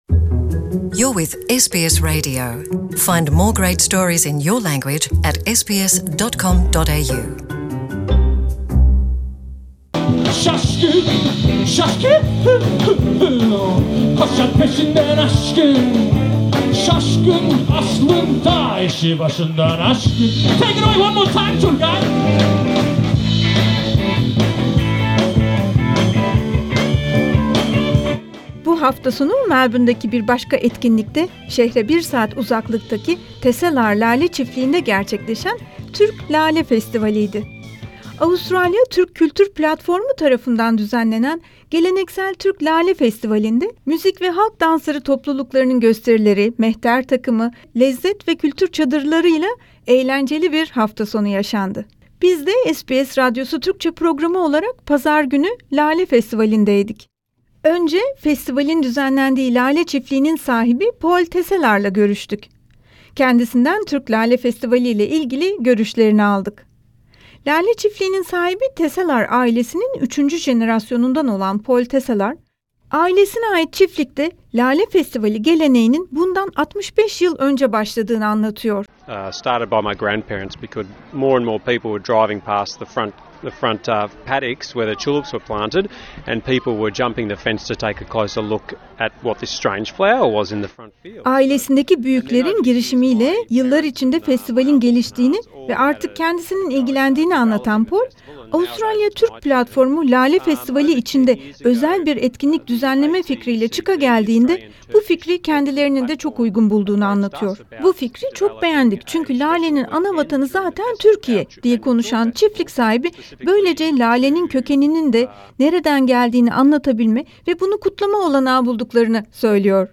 Bu hafta sonu Tesselaar Lale Bahçeleri'nde düzenlenen 14'ncü Geleneksel Türk Lale Festivali müzikler, danslar, kültürel standlar ve Türk lezzetleri eşliğinde gerçekleşti. SBS Radyosu Türkçe programı olarak biz de oradaydık. Festivale katılan Türk toplum bireyleriyle ve festivalin ilgilileriyle konuştuk, bu yılın değerlendirmelerini aldık.